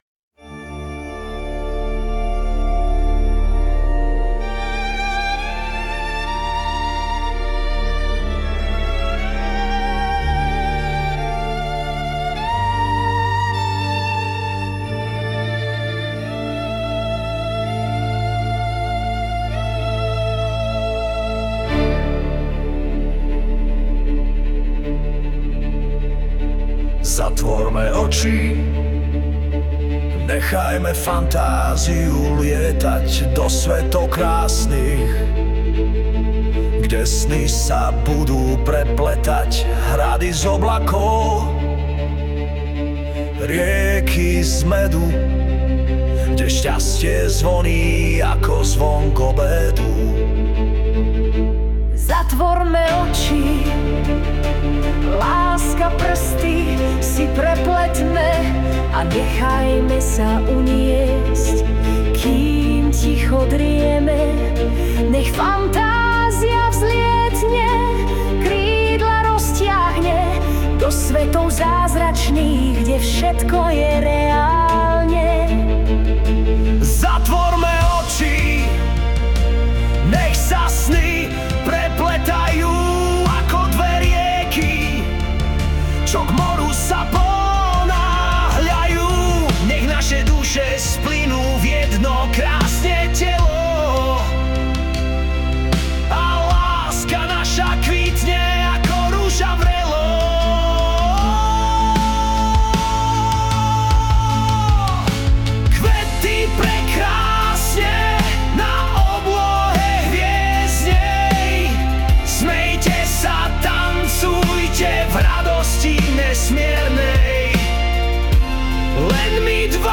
Hudba a spev AI
varhany a housle
úvod opět jako pozvánka do symfonie